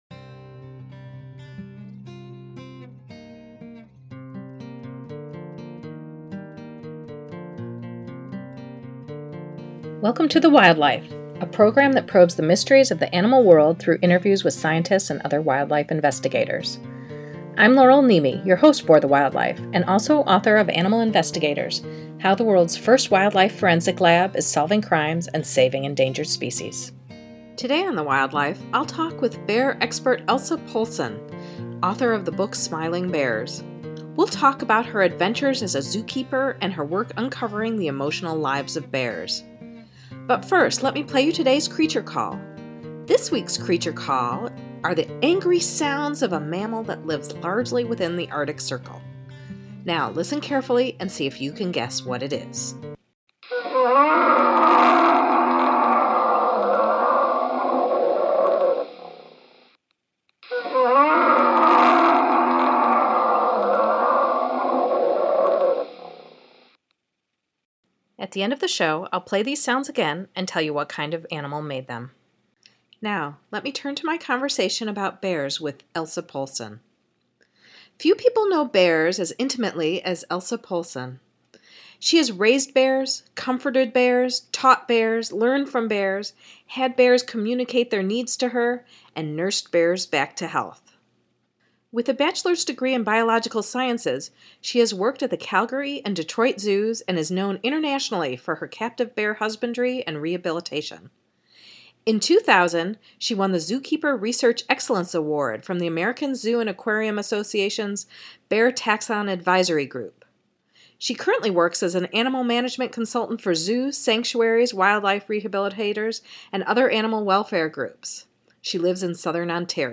talks about her adventures as a zookeeper and her work uncovering the emotional lives of bears in this first of a two-part interview.